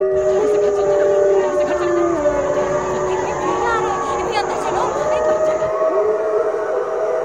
• wolves howl with frightened voices.ogg
[wolves-howl-sound-effect]-[frightened-voices-sound-effect]_ua6.wav